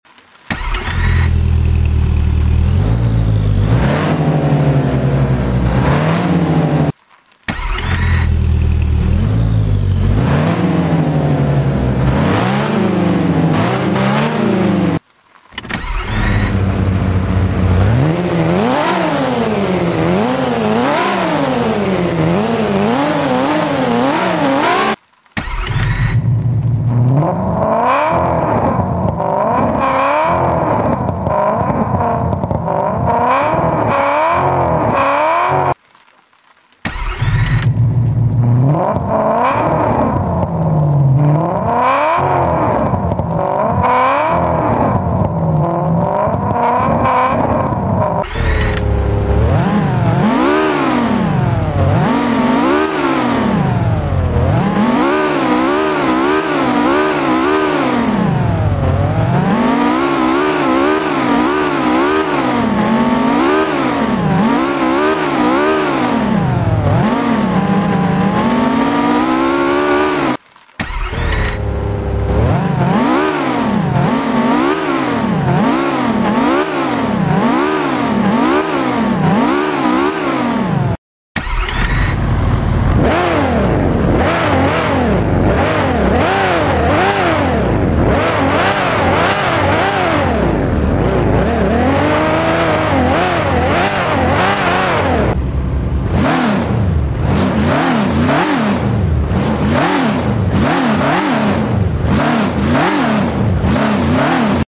Engine Sounds Comparison in CPM